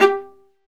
Index of /90_sSampleCDs/Roland - String Master Series/STR_Viola Solo/STR_Vla2 _ marc